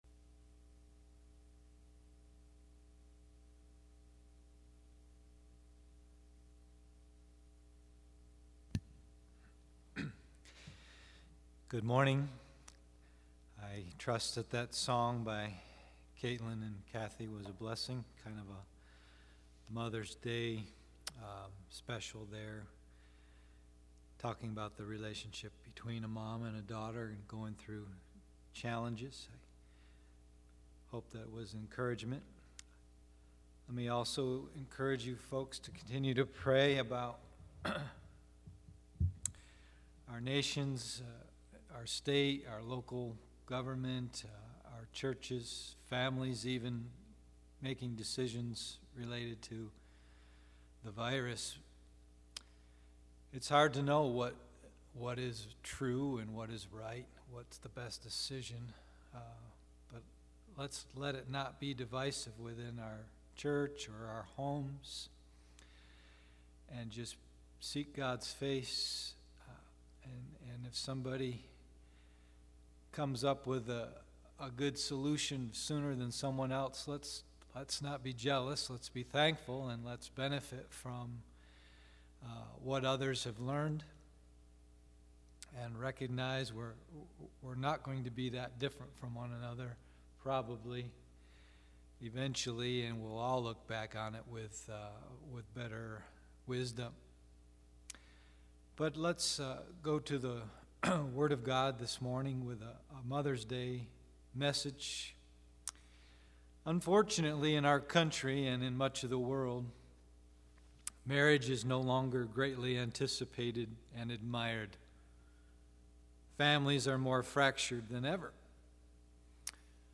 Mothers-Day-Message-2020.mp3